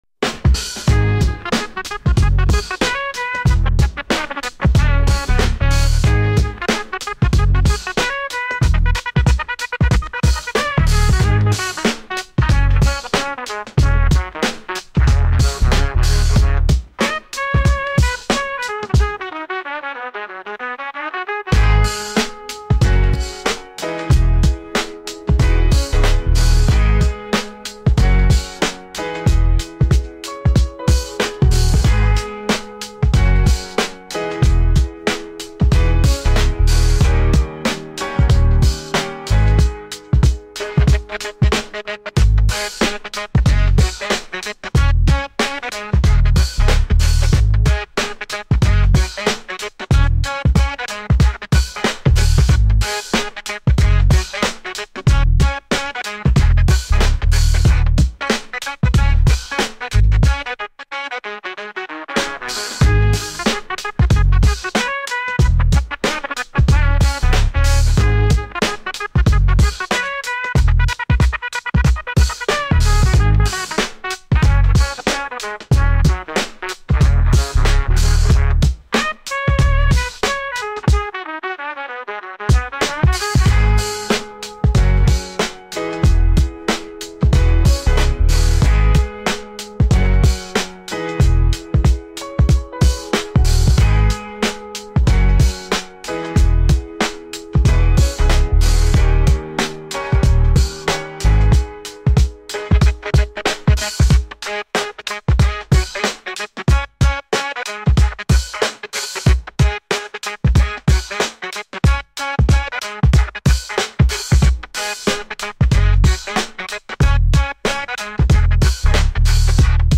your ultimate destination for calming vibes, chill beats